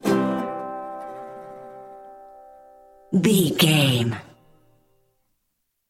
Ionian/Major
acoustic guitar
percussion